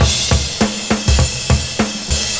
100CYMB12.wav